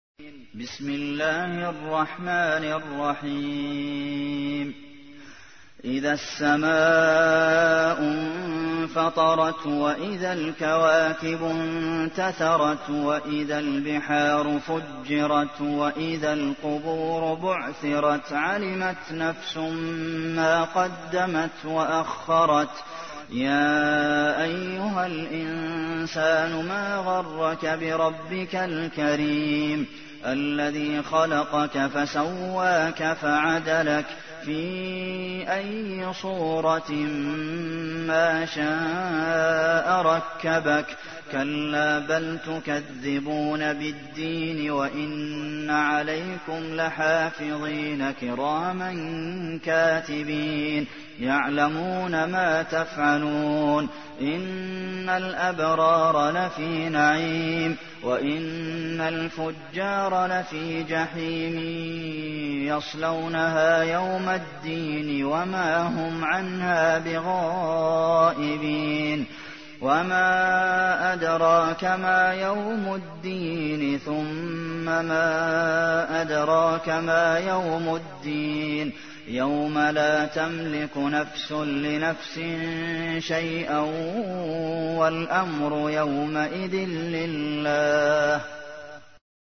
تحميل : 82. سورة الانفطار / القارئ عبد المحسن قاسم / القرآن الكريم / موقع يا حسين